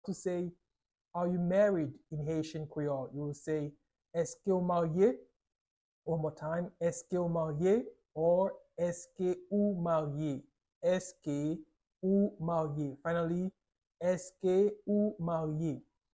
How to say “Are you married?” in Haitian Creole - “Èske ou marye?” pronunciation by a native Haitian Creole Teach
“Èske ou marye?” Pronunciation in Haitian Creole by a native Haitian can be heard in the audio here or in the video below: